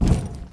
pl_duct1.wav